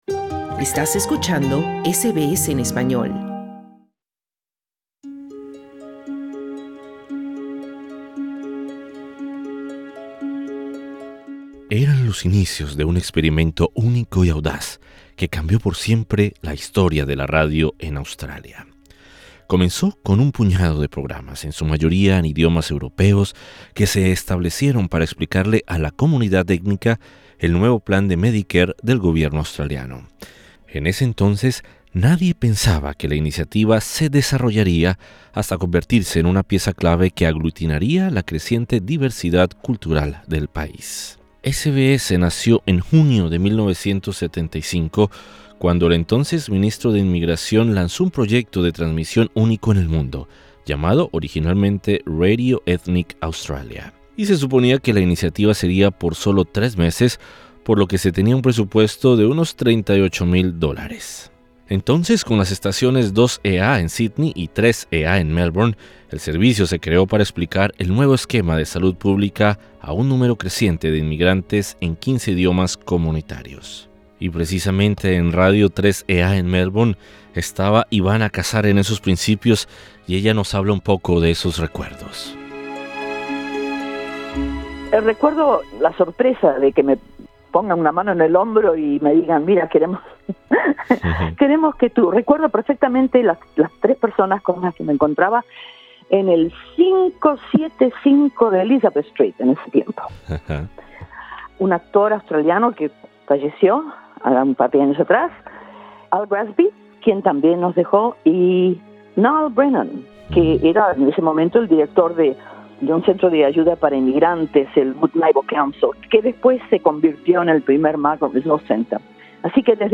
tiene una voz grave, calmada, que con su cadencia transmite autoridad y credibilidad a las noticias y la información que divulga SBS Spanish.